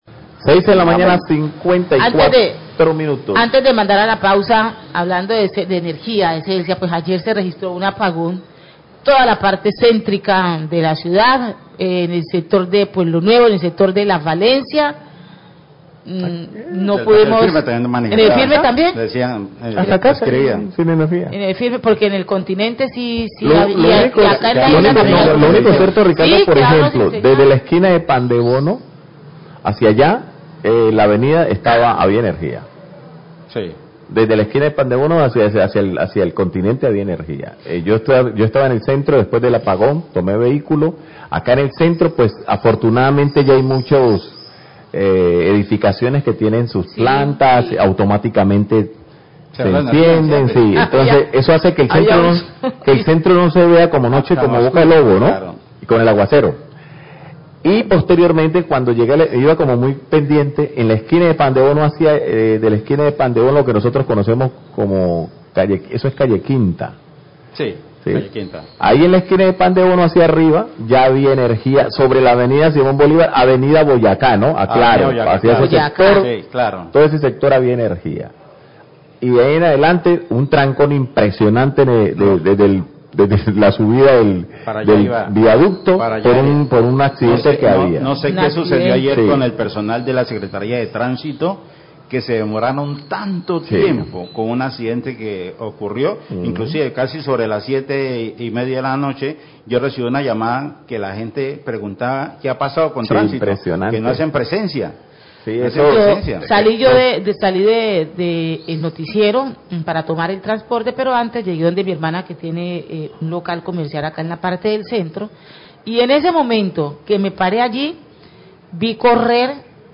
Periodista comenta sobre corte de energía en centro de Buenaventura y explicación de Celsia
Radio